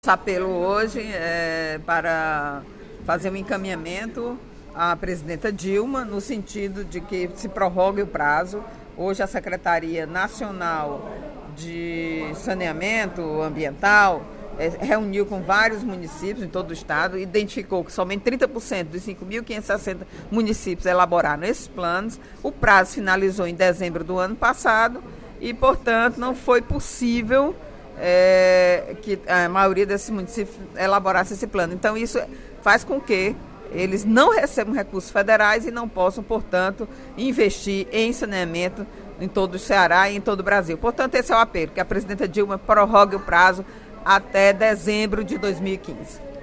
A deputada Eliane Novais (PSB) abriu os trabalhos da sessão plenária desta terça-feira (08/04), durante o primeiro expediente, fazendo um apelo à presidente Dilma Rousseff para que prorrogue o prazo de entrega dos planos de saneamento dos municípios brasileiros para 31 de dezembro.